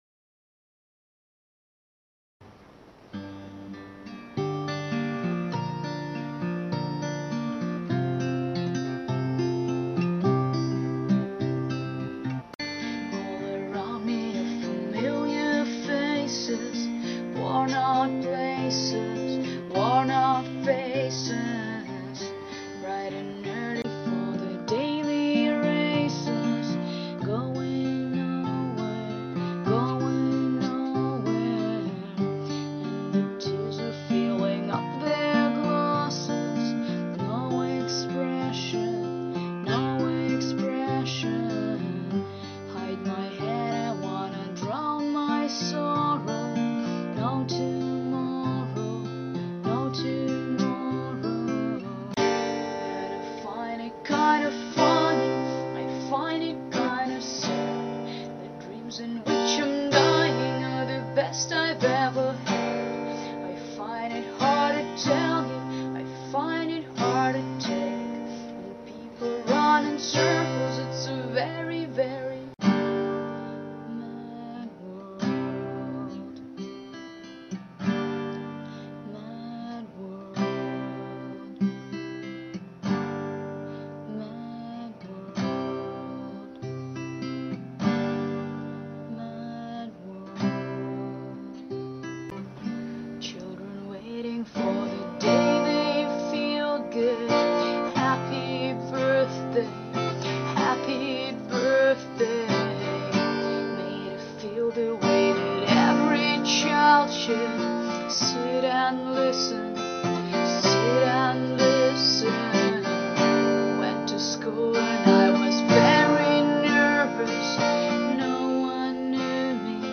((cover))
Verses: Em G D A
Chorus: Em A